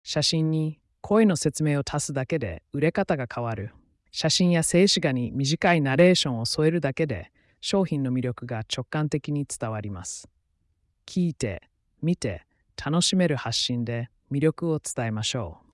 WEGEE Voice Studio は、テキストから自然な日本語音声をつくるスタジオ。
VOICE：ドラマチック女性（表現豊か）
VIBE：しっとり・落ち着き